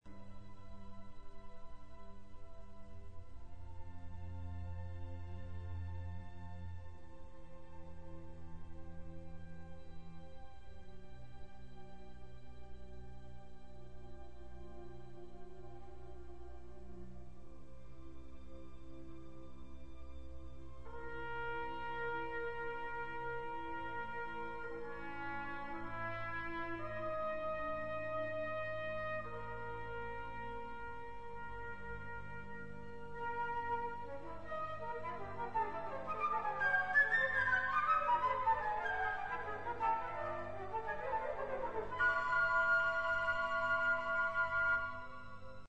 یک پس زمینه آهسته زهی، مجموعه ای از جمله های سازهای بادی چوبی که مدام ناهمخوان تر می شوند و یک “پرسش” مکرر و اسرار آمیز با ترومپت، اجزای این اثر می باشند.